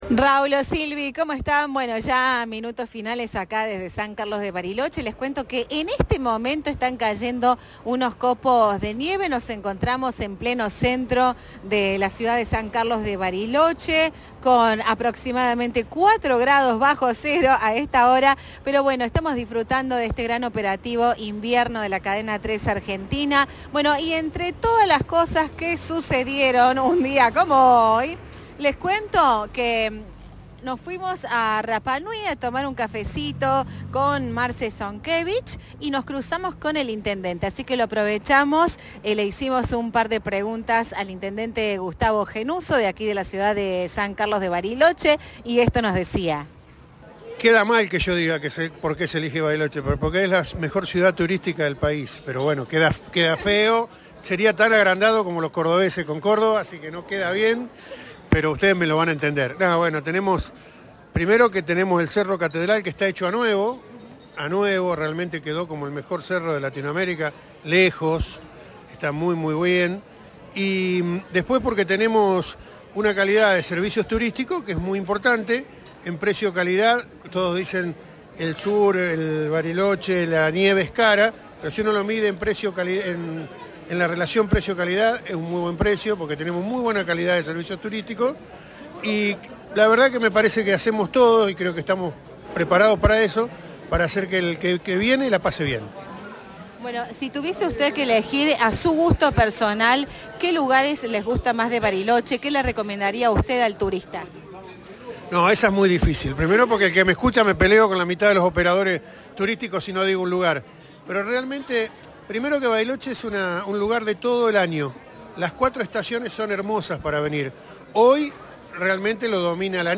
El intendente de la ciudad Gustavo Gennuso expresó en diálogo con Cadena 3 que la ciudad está preparada para recibir turismo de todo el mundo y que todos los años crece la cantidad de visitantes.
Entrevista